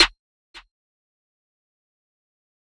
(SNARE) Culture National Anthem.wav